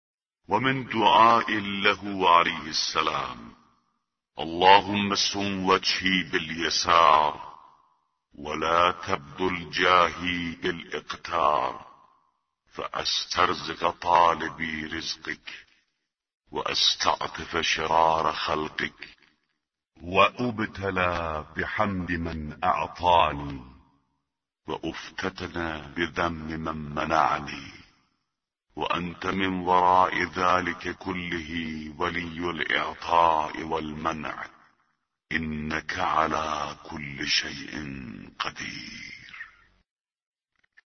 به گزارش وب گردی خبرگزاری صداوسیما؛ در این مطلب وب گردی قصد داریم، خطبه شماره ۲۲۵ از کتاب ارزشمند نهج البلاغه با ترجمه محمد دشتی را مرور نماییم، ضمنا صوت خوانش خطبه و ترجمه آن ضمیمه شده است: